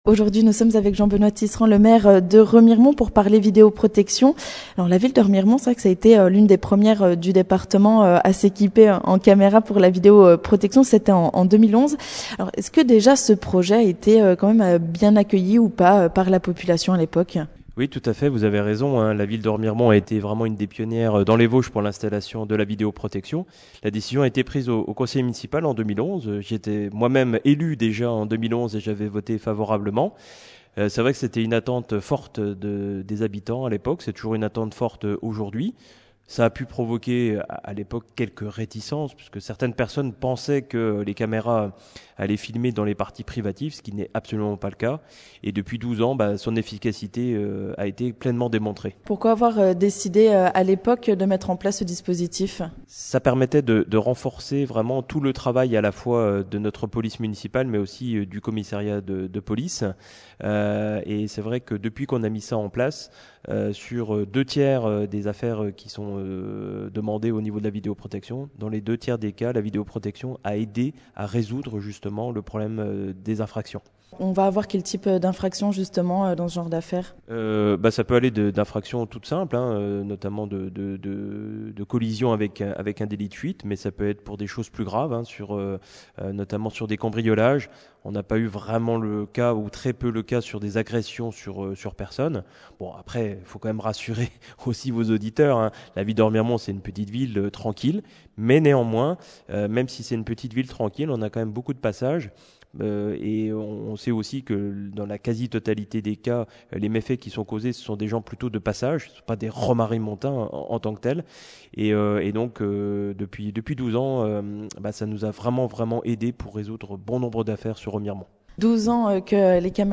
Les caméras de vidéo protection se multiplient dans les Vosges. L'occasion pour nous de tendre notre micro à Jean-Benoît Tisserand. Il est maire de Remiremont, l'une des villes pionnières en la matière.